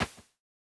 landing.wav